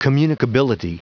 Prononciation du mot communicability en anglais (fichier audio)
Prononciation du mot : communicability